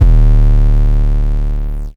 DIST1M808.wav